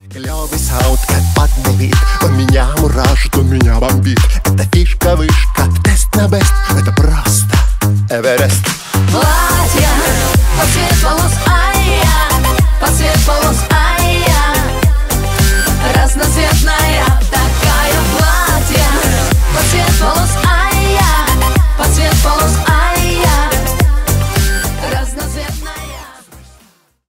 Поп Музыка # весёлые